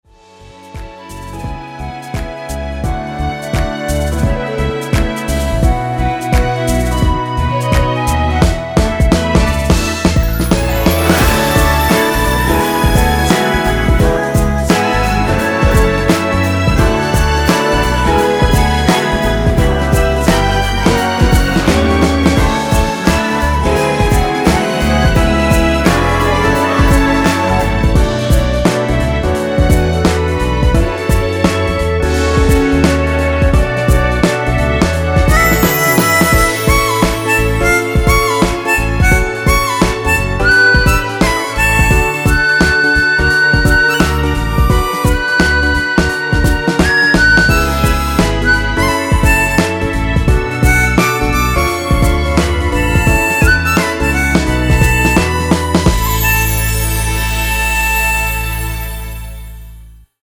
엔딩이 페이드 아웃이라서 노래하기 편하게 엔딩을 만들어 놓았으니 미리듣기 확인하여주세요!
원키에서(+1)올린 멜로디와 코러스 포함된 MR입니다.
◈ 곡명 옆 (-1)은 반음 내림, (+1)은 반음 올림 입니다.
앞부분30초, 뒷부분30초씩 편집해서 올려 드리고 있습니다.